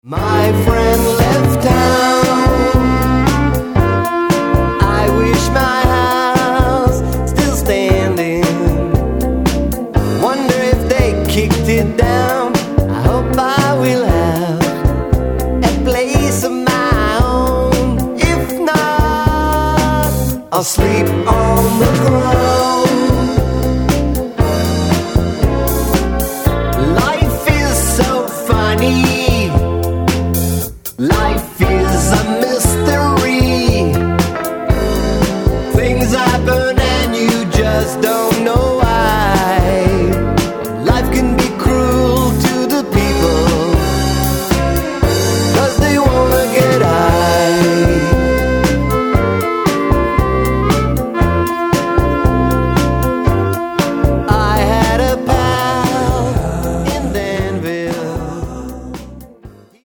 Four brothers and a cousin who play music.